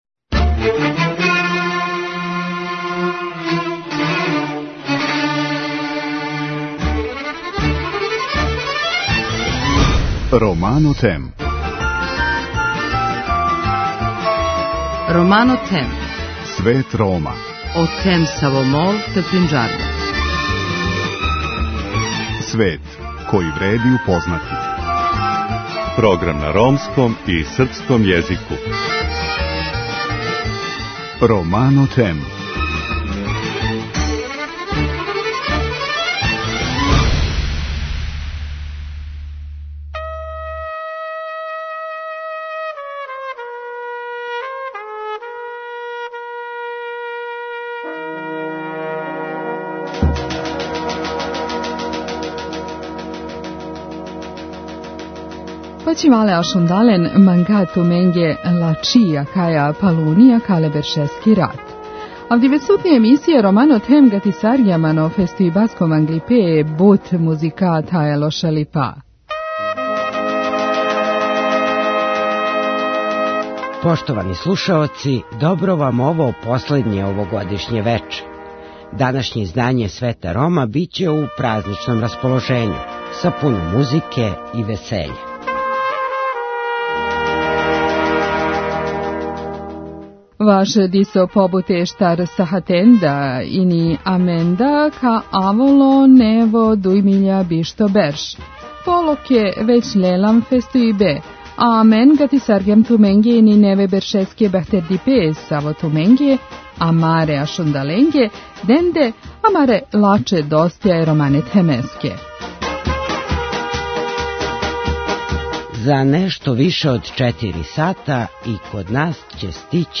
Данашње издање Света Рома биће у празничном расположењу, са пуно музике и весеља.
У последњем овогодишњем издању емисије, осим доброг расположења и добре музике, припремили смо новогодишње честитке које су управо вама, нашим слушаоцима, упутили гости Света Рома.